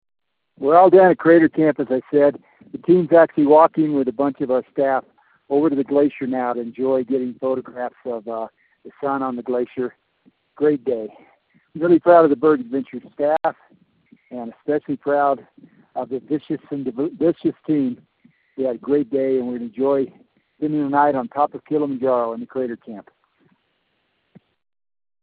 From Crater Camp